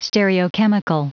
Prononciation du mot stereochemical en anglais (fichier audio)
Prononciation du mot : stereochemical